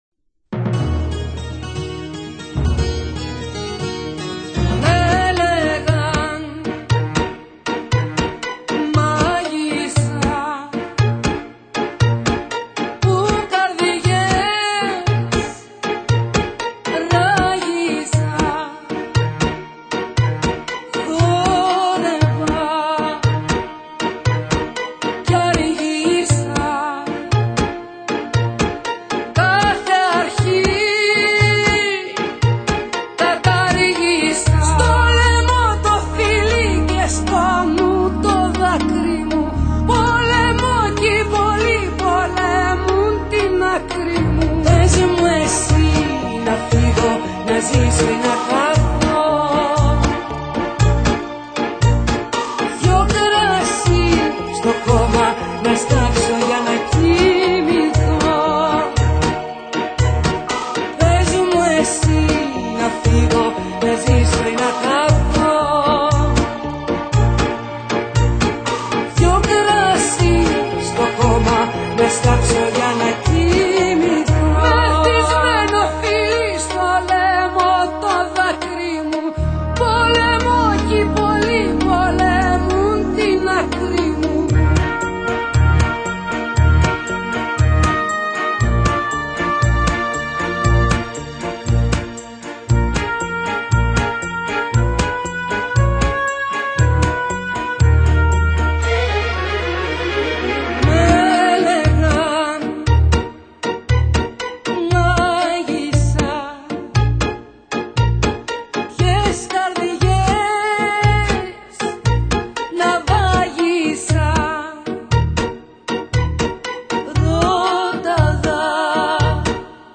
Tango(탱고)